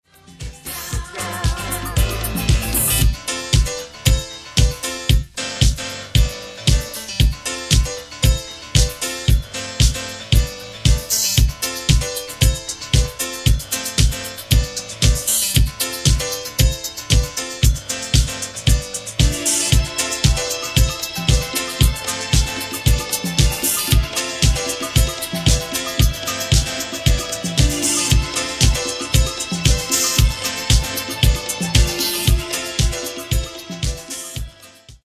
Genre:   Latin Disco